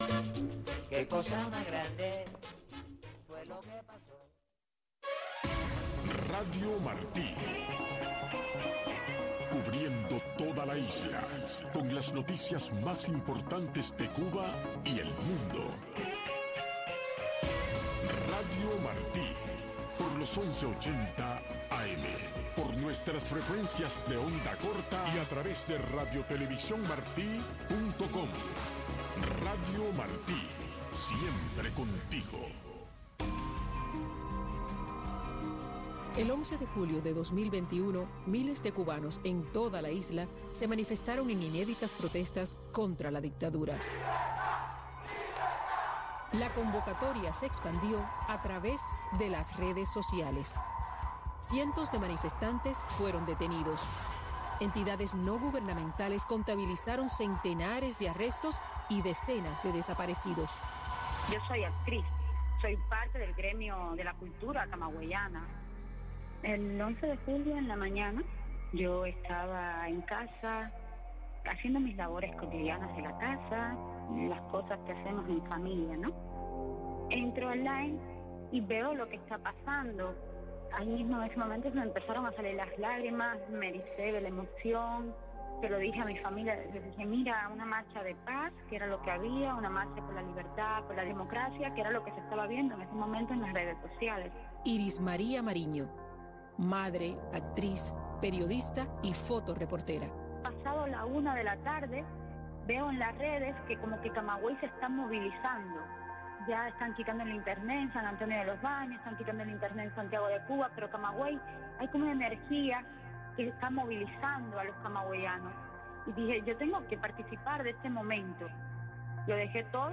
astrónomos de la NASA, biólogos, doctores en Meteorología, expertos en Biodiversidad, entre otros